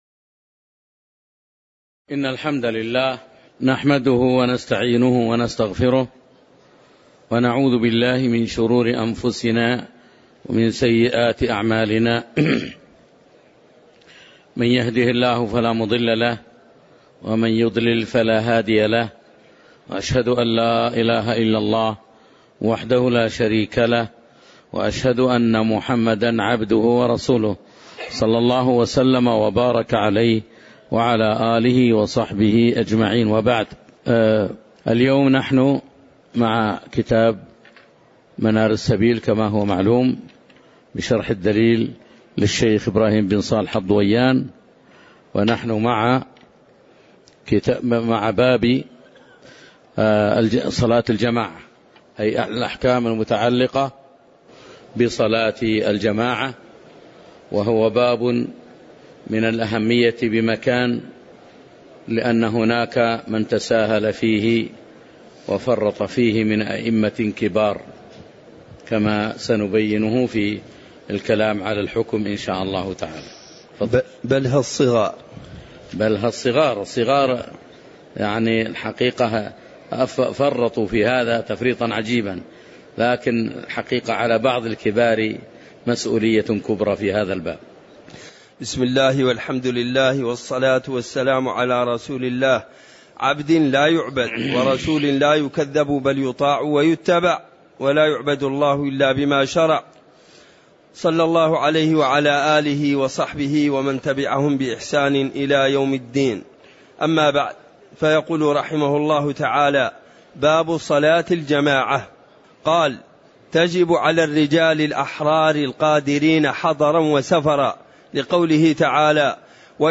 تاريخ النشر ٢٥ محرم ١٤٣٩ هـ المكان: المسجد النبوي الشيخ